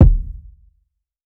Detox Kick.wav